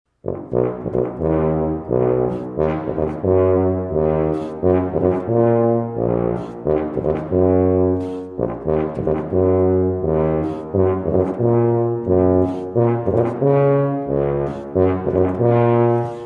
Звуки тубы
Игра на тубе для начинающих